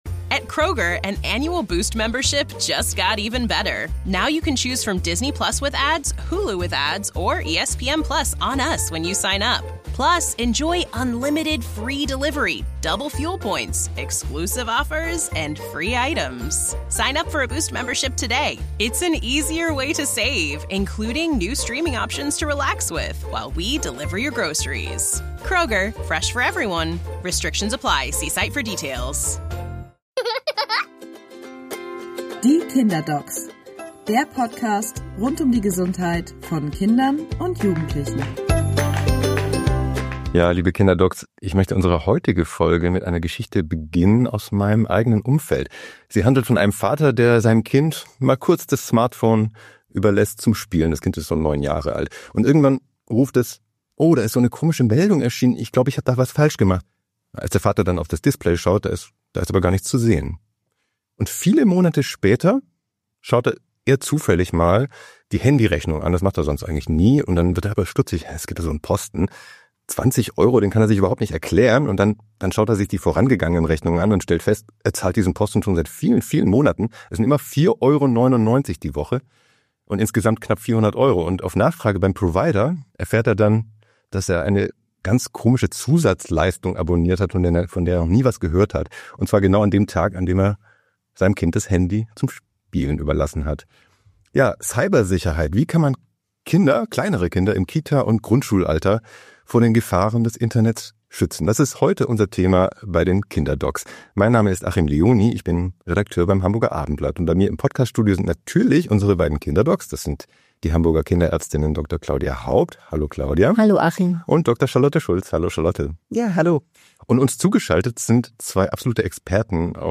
Warum, erklären zwei Datenschutzexperten.